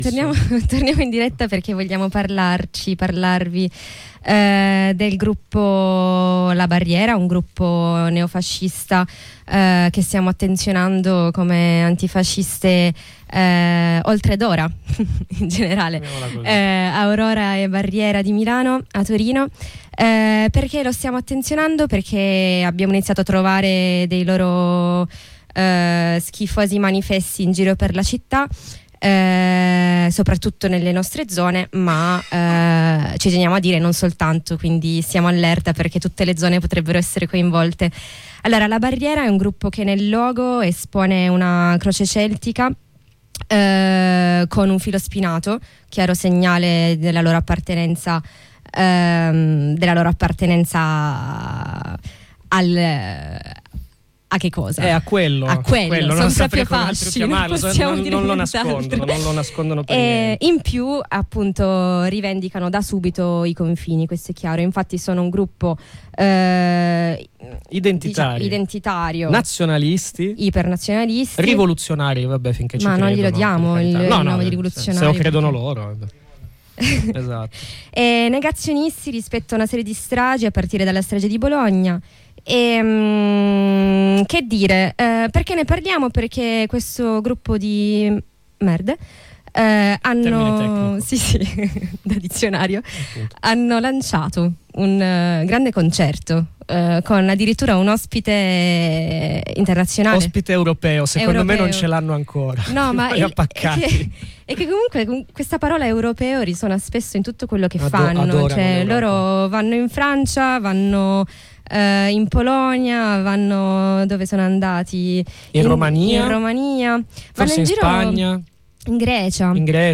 Ne parliamo ai microfoni di Radio Black Out con un militante antifascista.